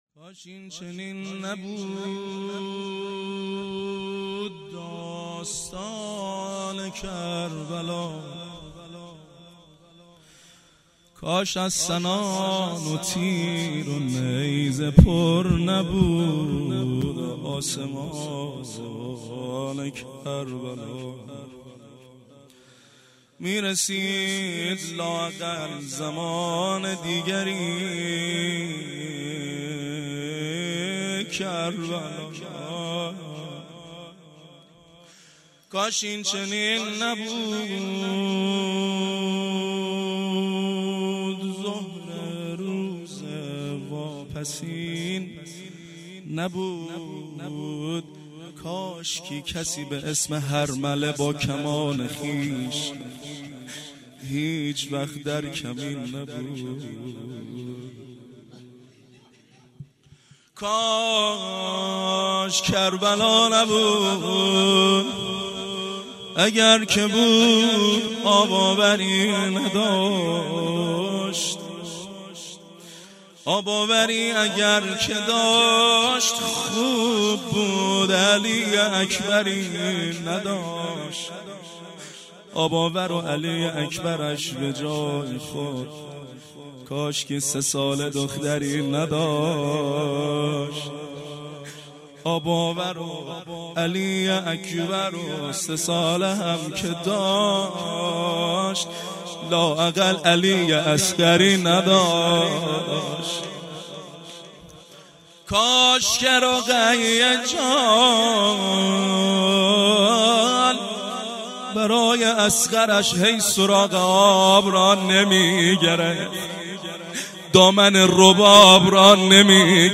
عزاداری سالروز تخریب بقیع